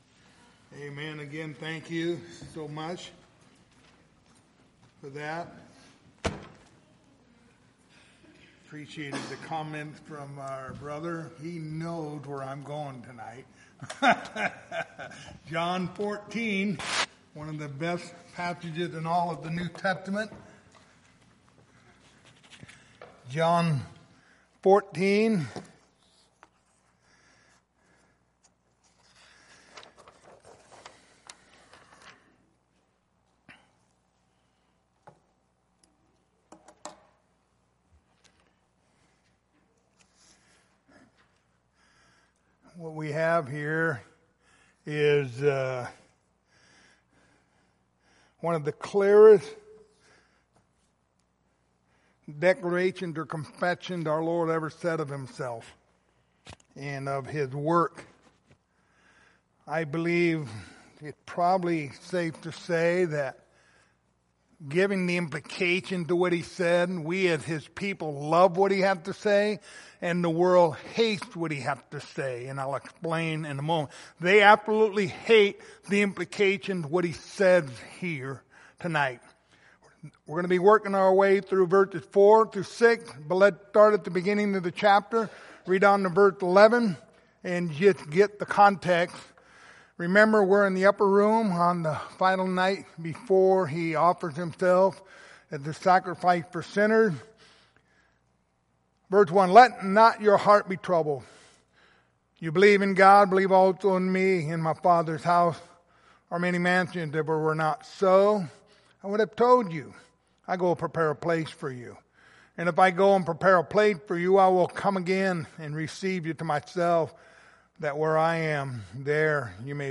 Passage: John 14:4-6 Service Type: Wednesday Evening